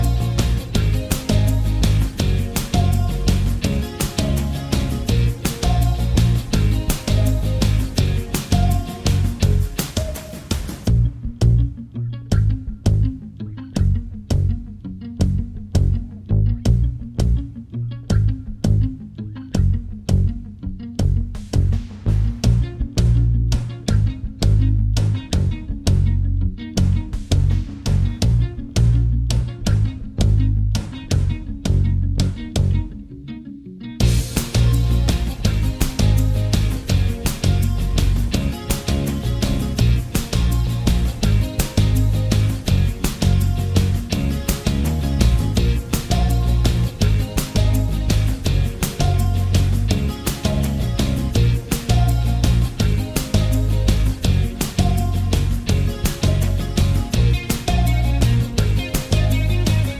Minus Main Guitars For Guitarists 3:10 Buy £1.50